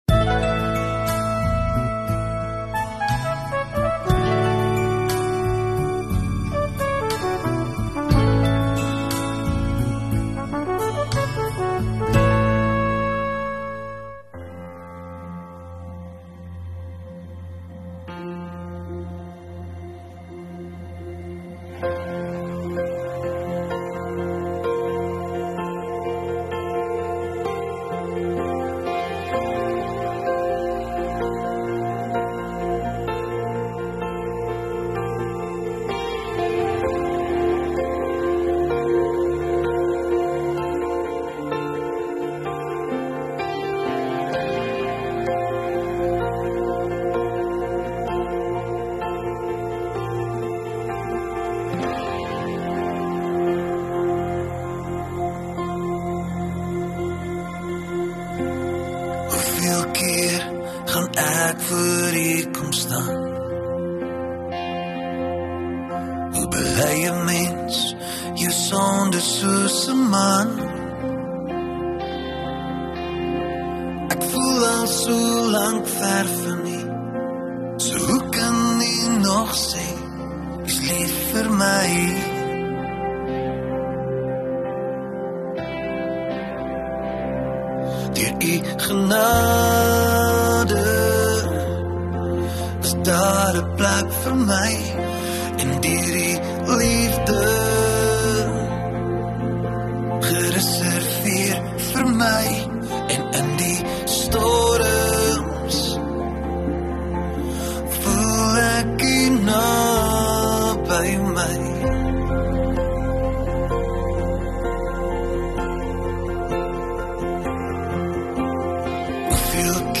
30 Mar Saterdag Oggenddiens